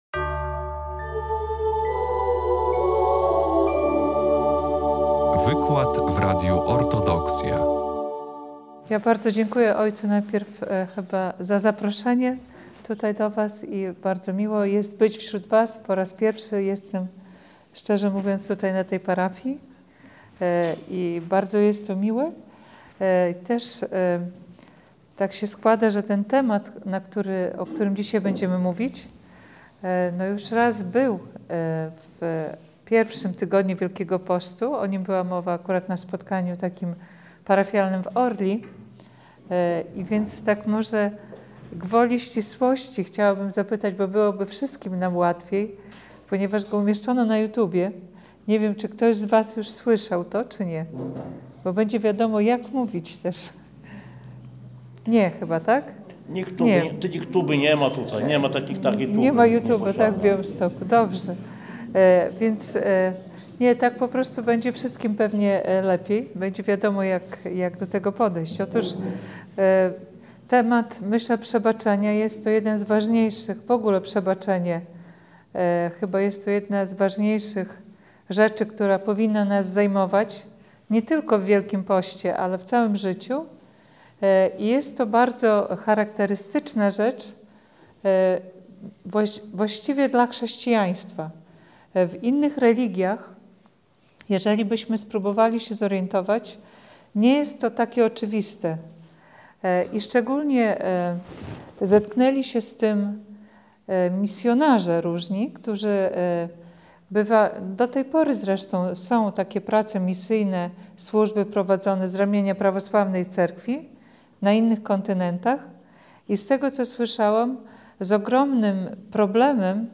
23 marca 2025 roku w domu parafialnym przy cerkwi św. Grzegorza Peradze w Białymstoku
wykład